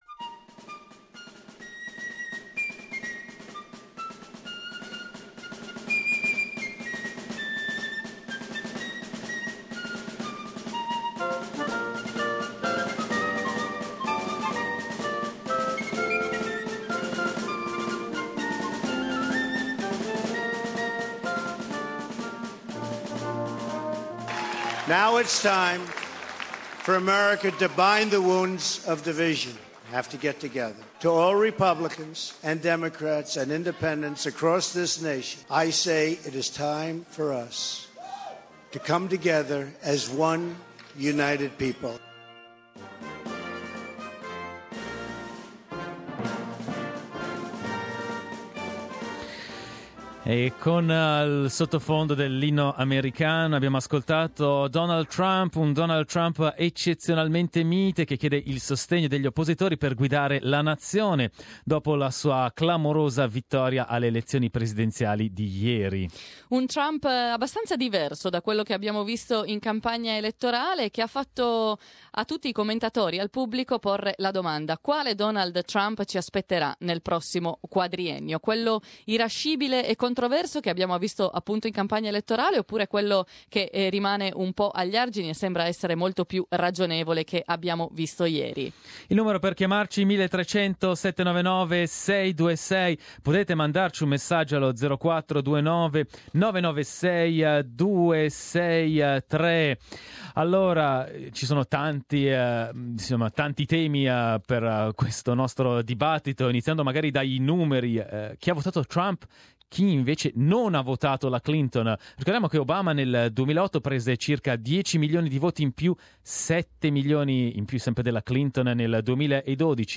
The result of the US presidential election has taken many by surprise, how did you react? We asked the same question to our audience during the Thursday talkback.